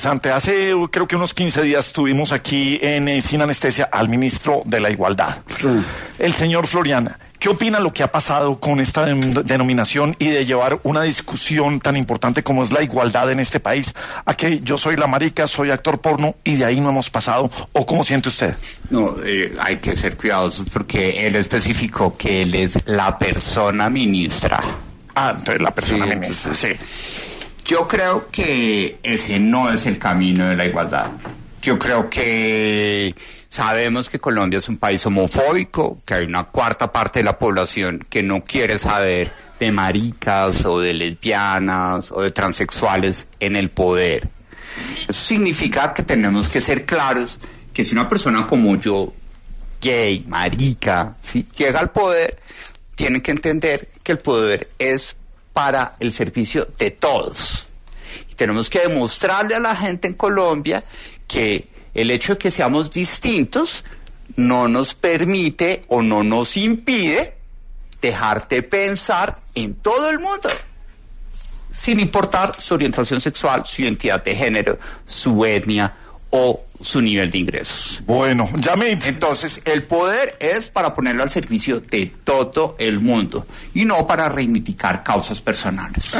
En entrevista reciente en “Sin Anestesia”, Juan Daniel Oviedo se refirió sobre algunas actuaciones de Juan Carlos Florián como ministro de igualdad.